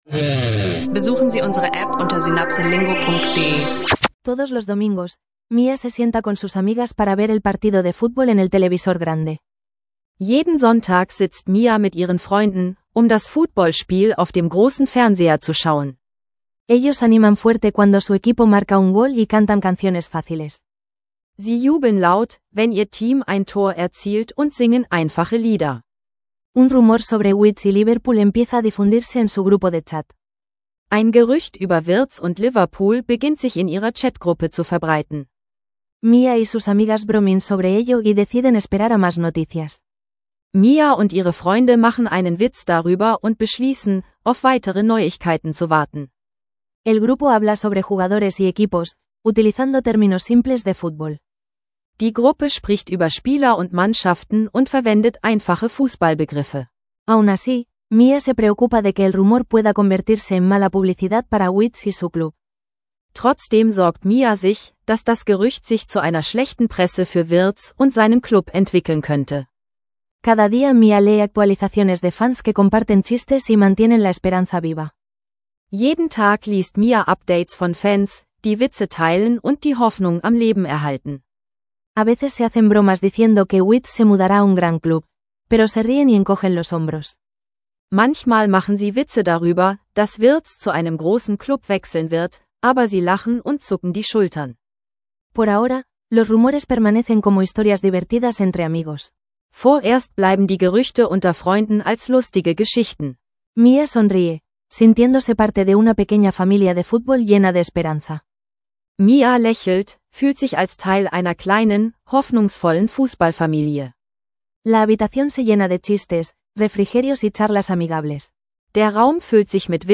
Lerne Spanisch mit wörtlichen Dialogen aus einer leichten Fußball-Geschichte – Spanisch lernen Podcast für Anfänger und Fortgeschrittene